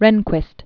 (rĕnkwĭst), William Hubbs 1924-2005.